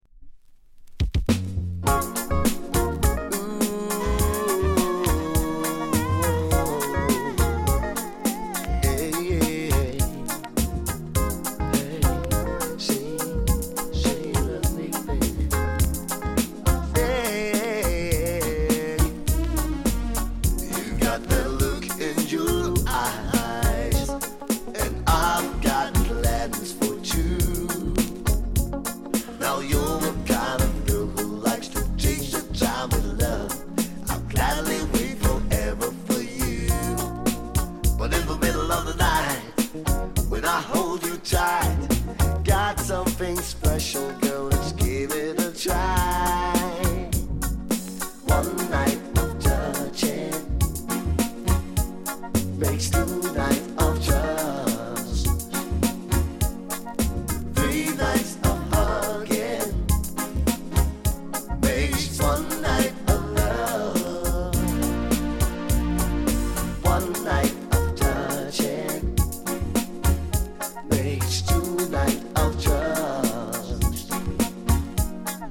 R＆B～LOVERS
音に影響ない 軽い反り。